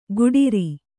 ♪ guḍiri